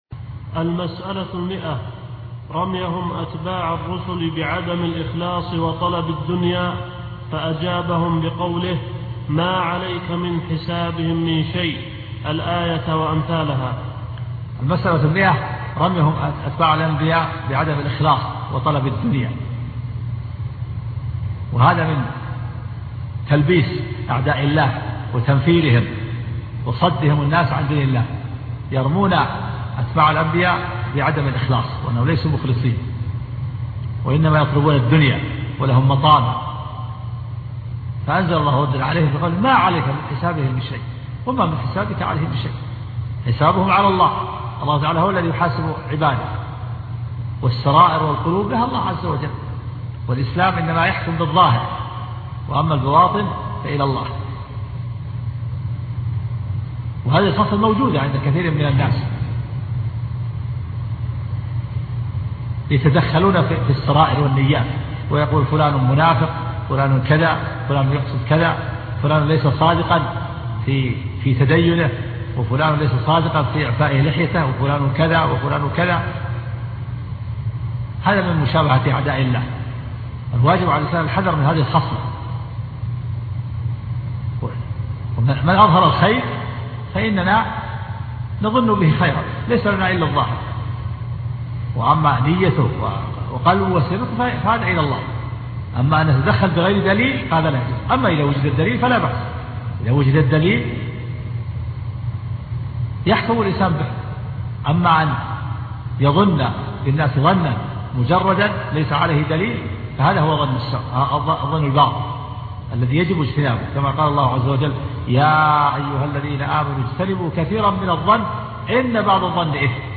الدرس 67